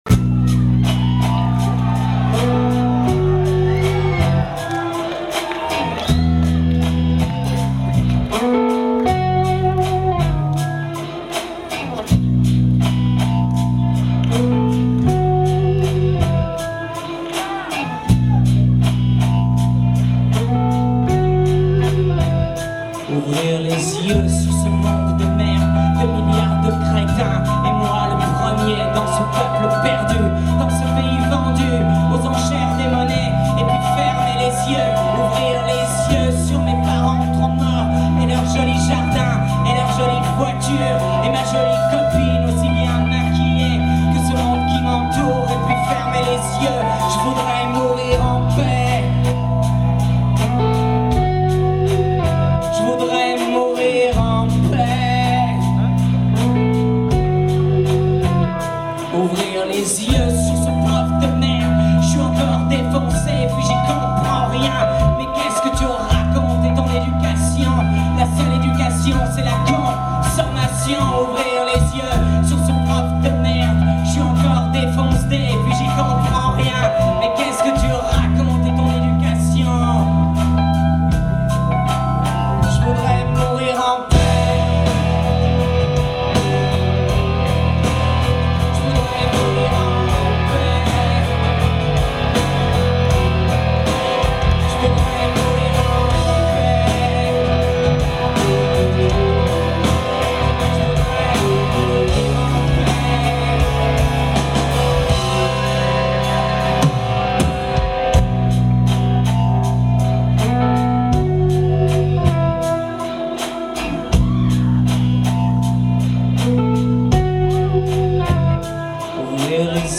Live